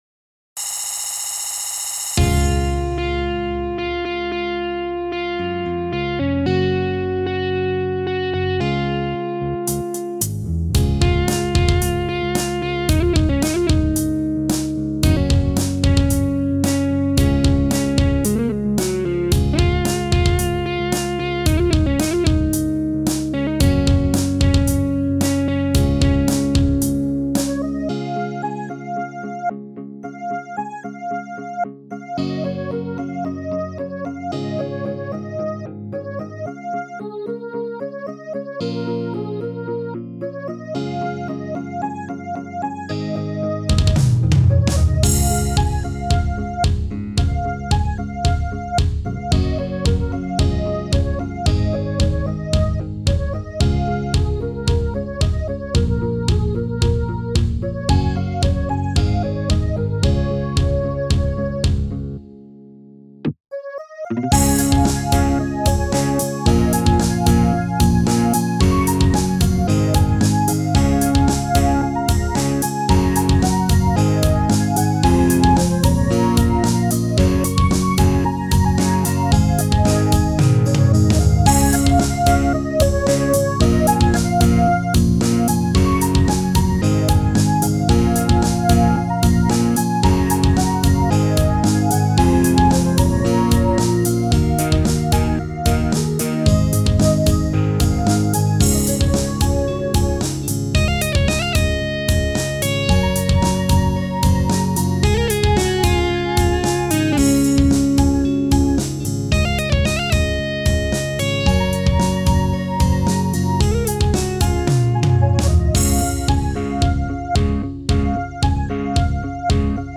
谱内音轨：人声，和声，主奏吉他，效果吉他，木吉他，Bass，架子鼓
曲谱类型：乐队总谱